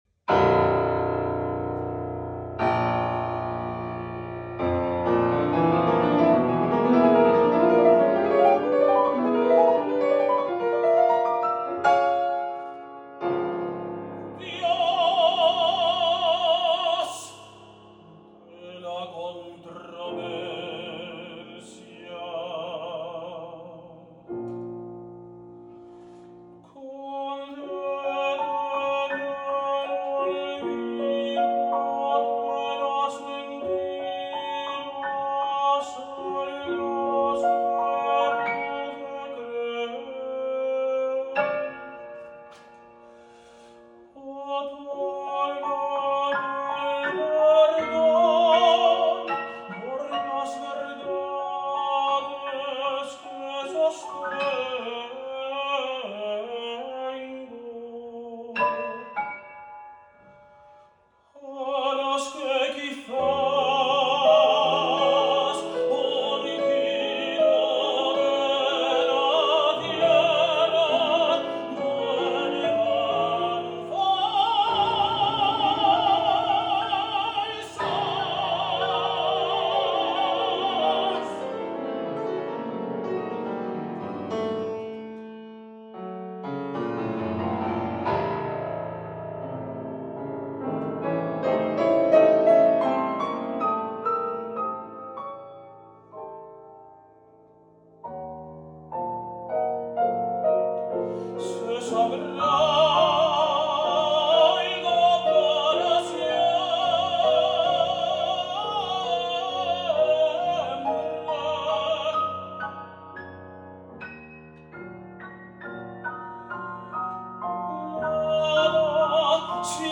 Instrumentación: Tenor/soprano y piano.